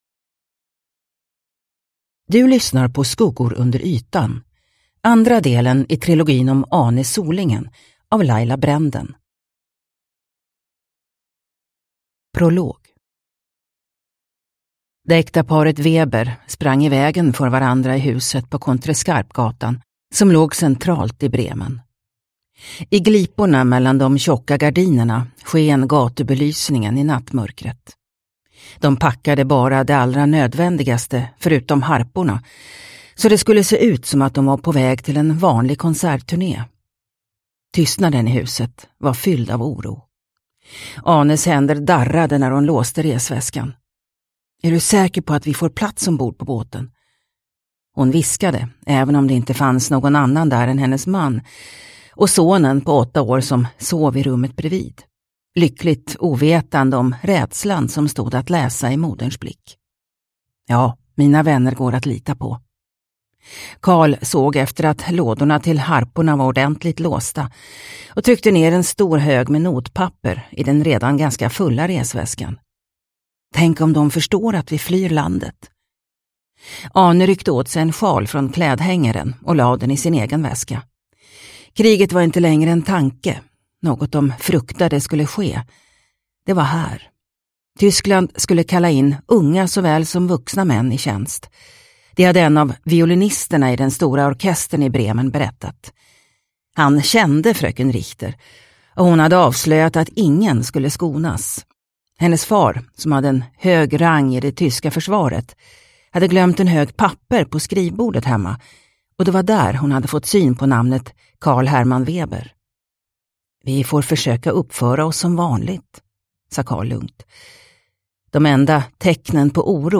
Skuggor under ytan – Ljudbok – Laddas ner